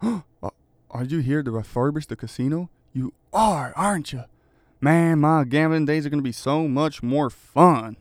Update Voice Overs for Amplification & Normalisation
GASP A Are you here ti refurbish the casino, you are arent you.wav